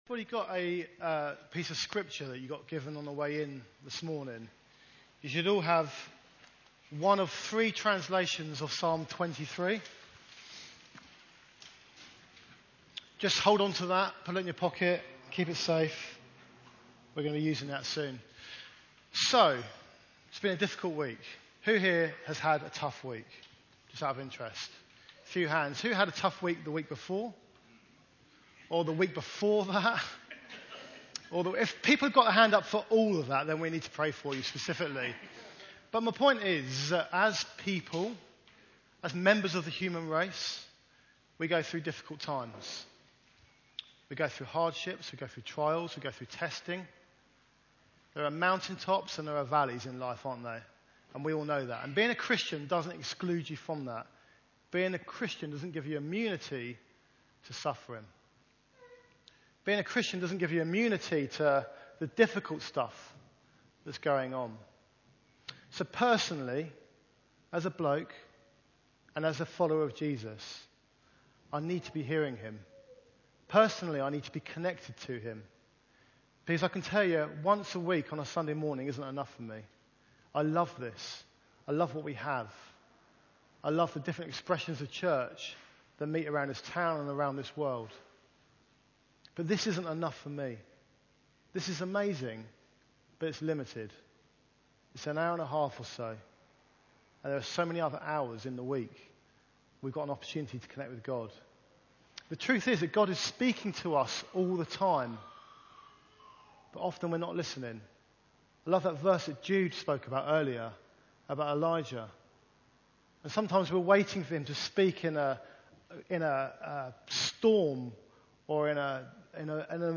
(Please note there is a 10min live ‘pause’ in the audio file, but the talk does continue.)